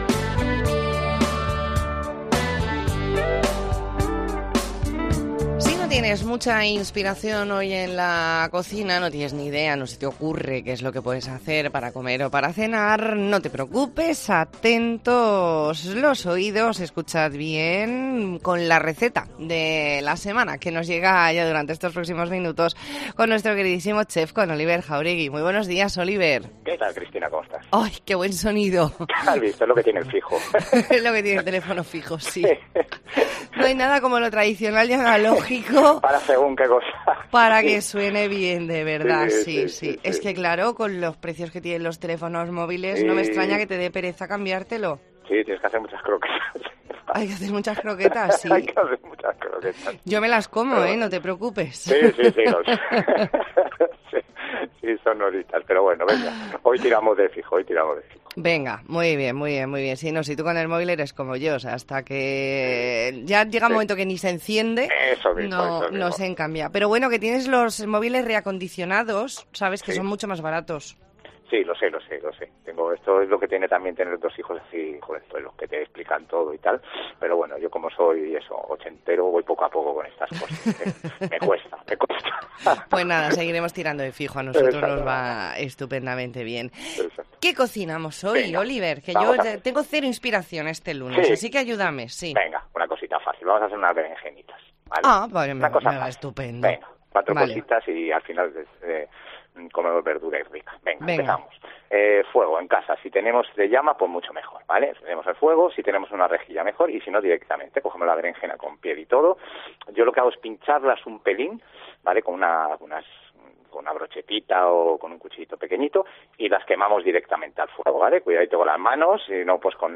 Entrevista en La Mañana en COPE Más Mallorca, lunes 17 de octubre de 2022.